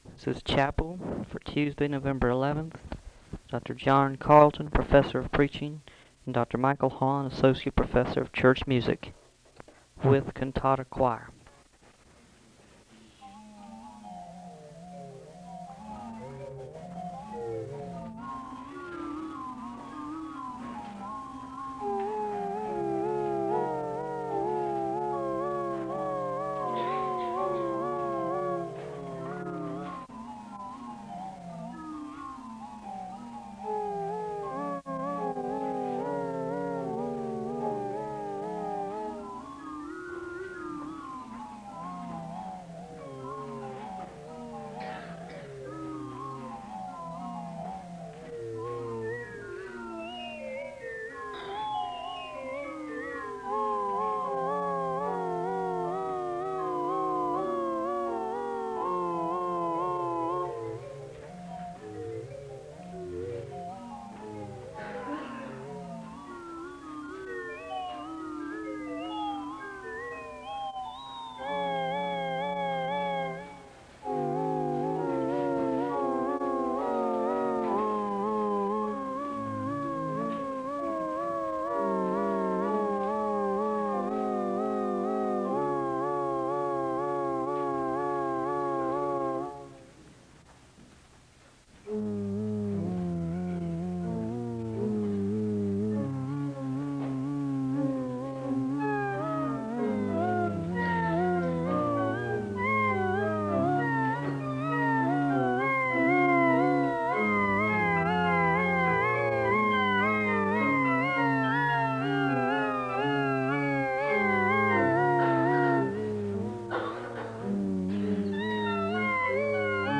SEBTS Chapel
The service begins with organ music (0:00-5:10). There is a moment of prayer (5:11-6:14).
The congregation is led in the unison prayer of confession (6:15-7:43). The choir sings songs of worship (7:44-16:47).
The choir continues singing songs of worship (25:44-34:02). There is a blessing to close the service (34:03-34:29).